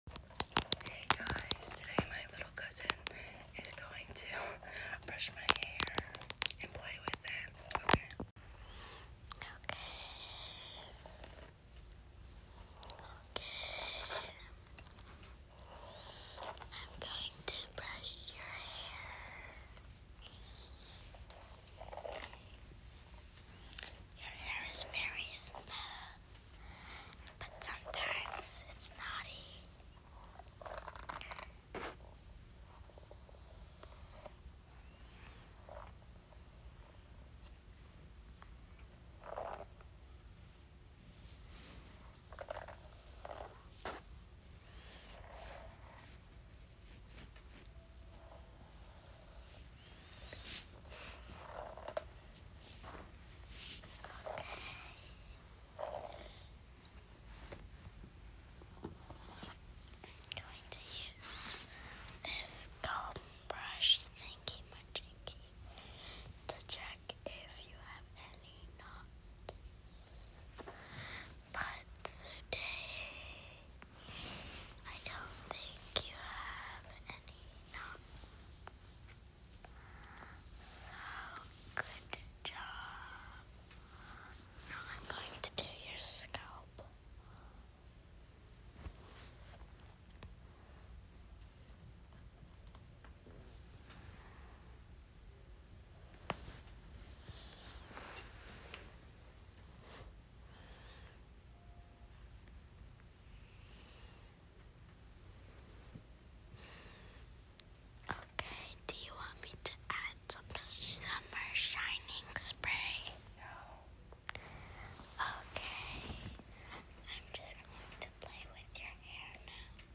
ASMR hair brushing and back sound effects free download
ASMR hair brushing and back scratches!!